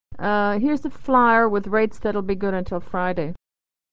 Unstressed 'your, you're' is reduced = /yər/